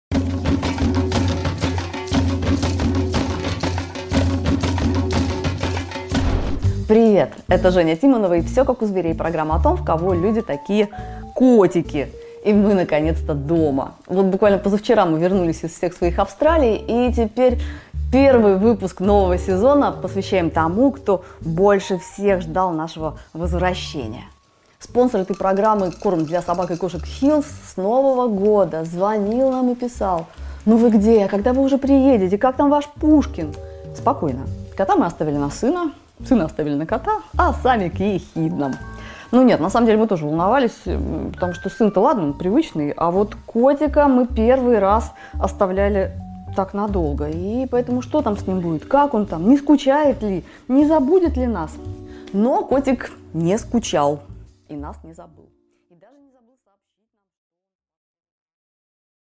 Аудиокнига Страшнее кошки зверя нет | Библиотека аудиокниг